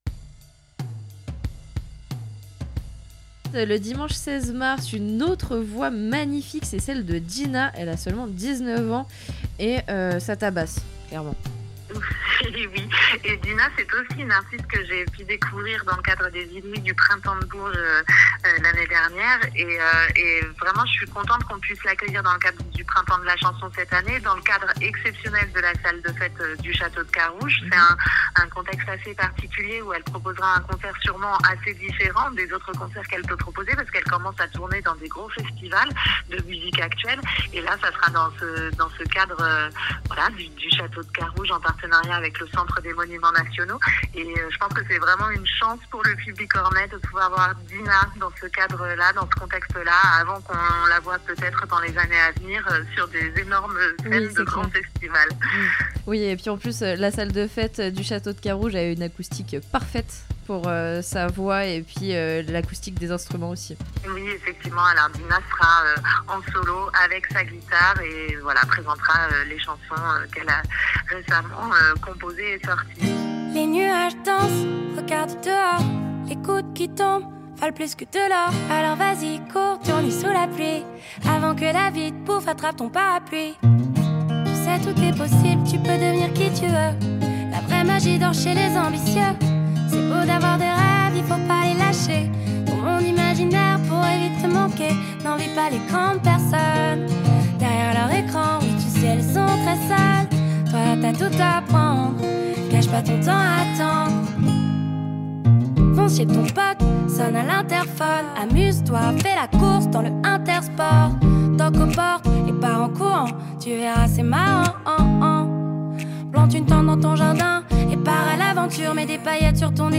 Interviews RCDF